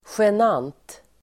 Ladda ner uttalet
genant adjektiv, embarrassing , awkward Uttal: [sjen'an:t (el. -'ang:t)] Böjningar: genant, genanta Synonymer: penibel, pinsam Definition: pinsam, besvärande Exempel: en genant situation (an awkward situation)